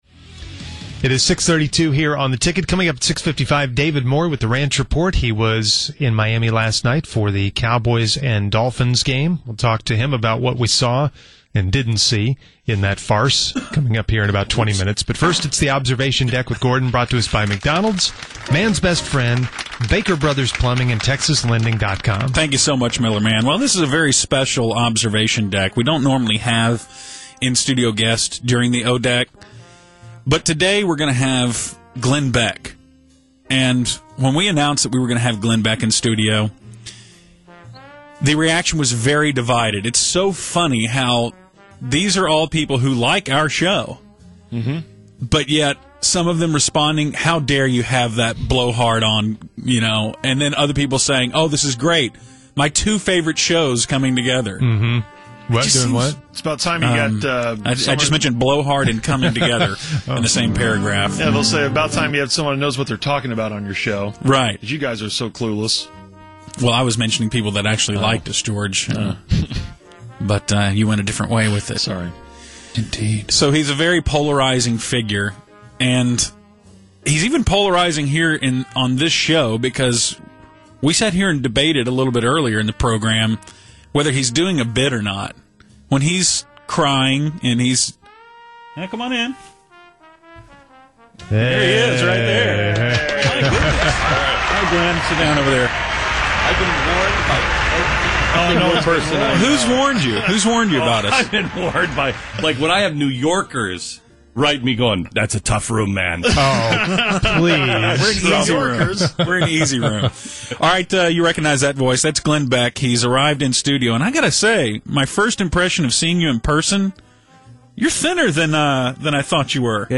Through an odd and still unexplained confluence, the Musers had Glenn Beck live in studio for the O-Deck on Friday Morning. A pretty interesting interview, regardless of what your political leanings might be.